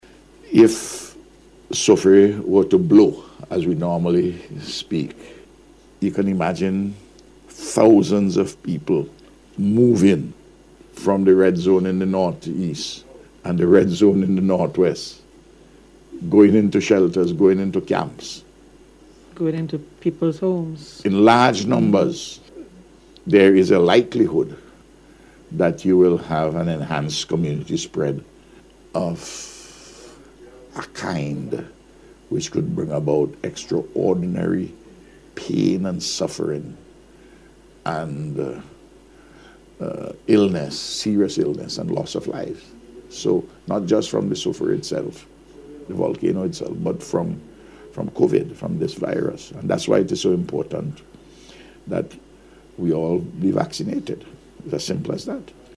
Dr. Gonsalves made the point, during the Round Table Talk programme last night.